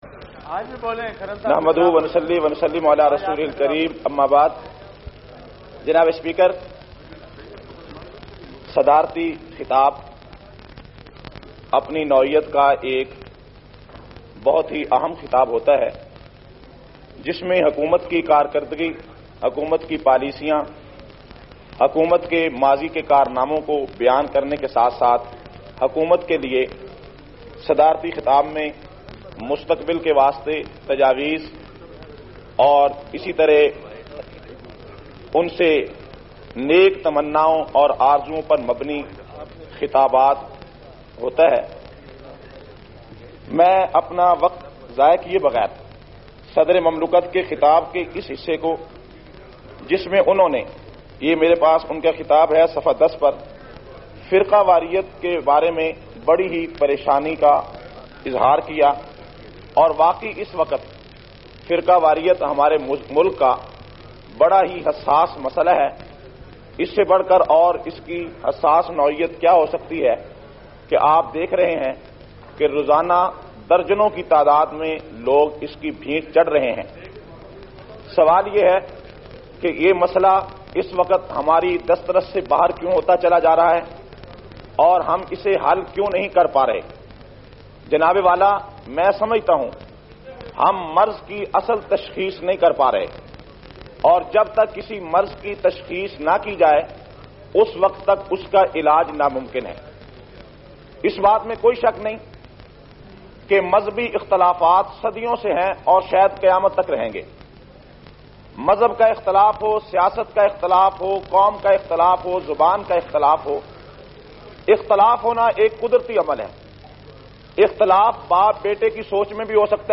389- Firqa Wariyat kya hai Assembly Khitab.mp3